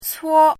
怎么读
cuō